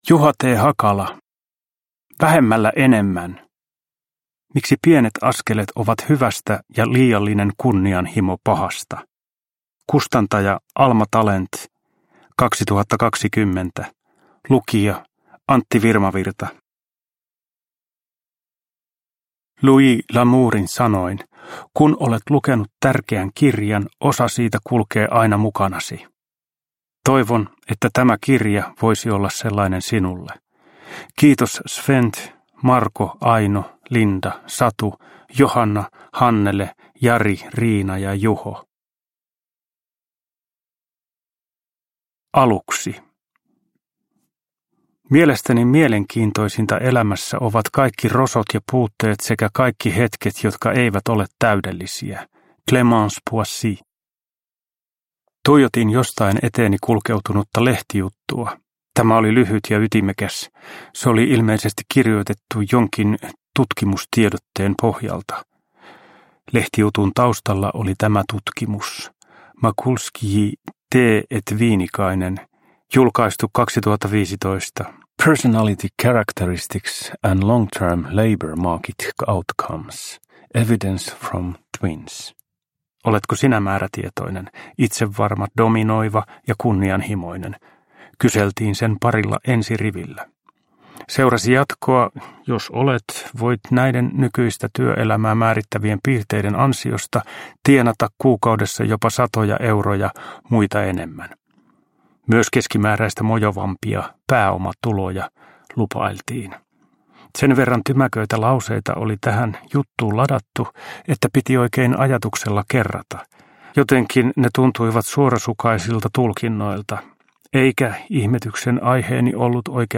Vähemmällä enemmän – Ljudbok – Laddas ner